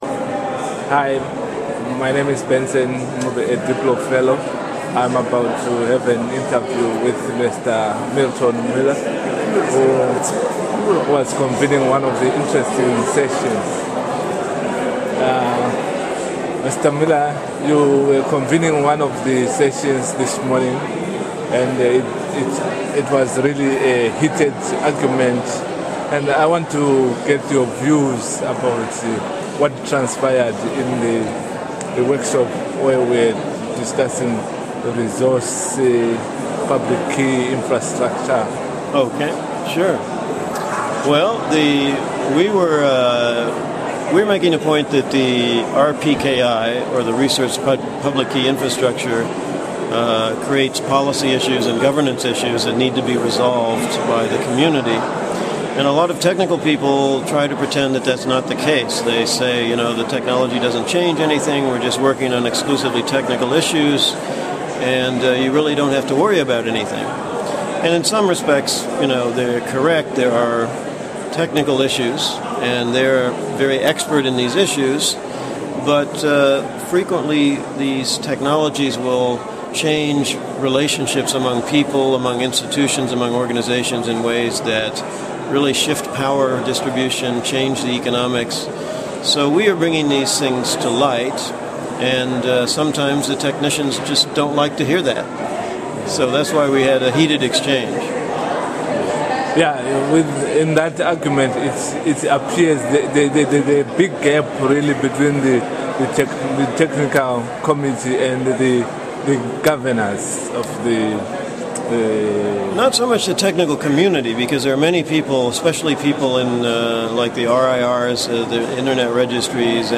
Interviews
at IGF